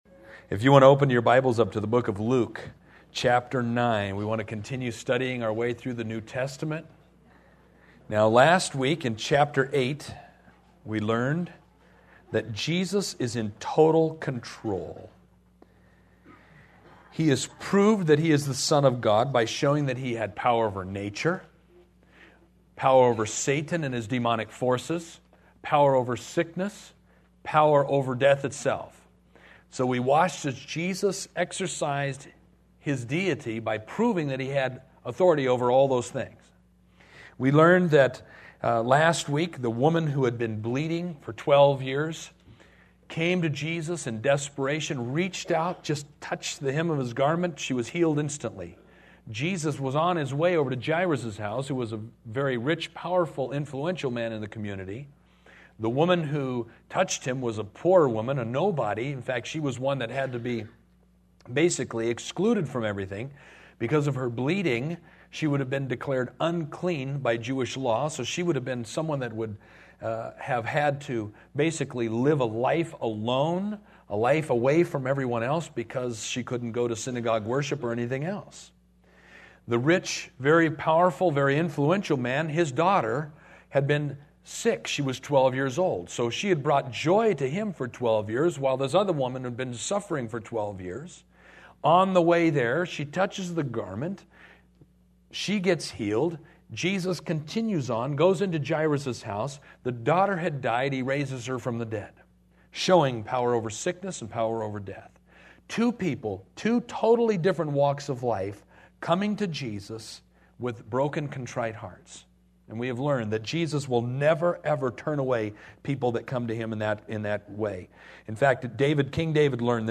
2014 Women’s Retreat